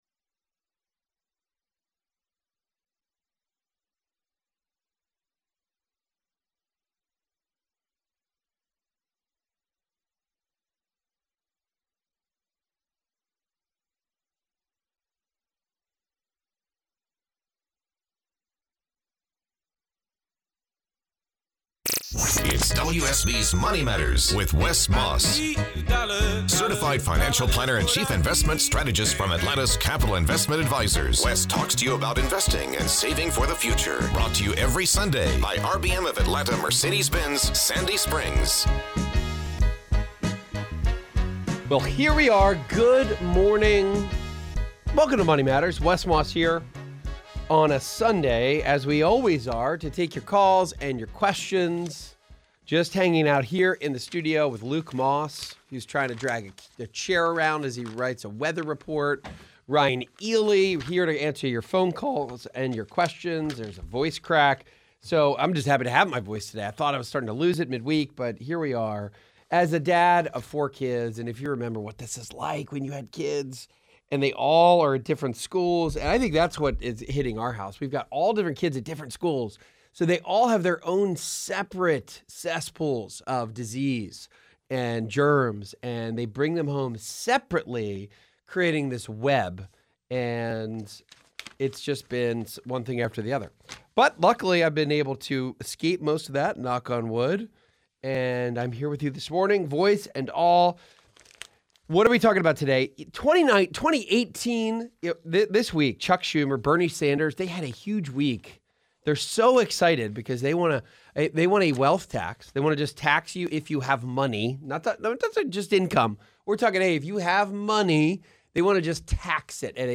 This episode of MM is chock full of calls relating to Roth IRAs, planning for grandchildren with special needs, finding the right annuity, multi-income stream planning with the TSP plan, and future nest egg planning.